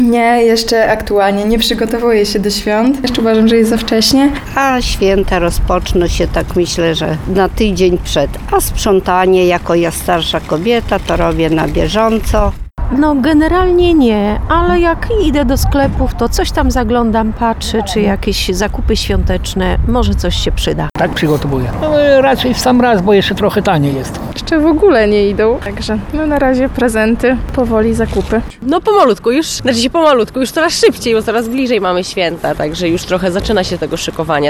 Zapytaliśmy przechodniów na ulicach Suwałk, jak przebiegają przygotowania w ich domach.